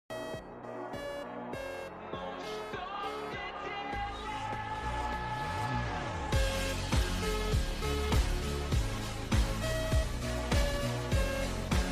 1 million beers Meme Sound Effect